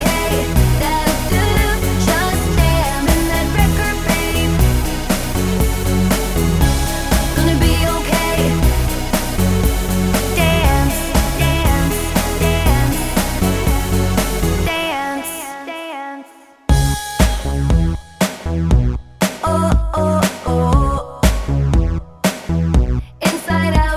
Duet Dance 3:59 Buy £1.50